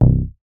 MoogUgly 002.WAV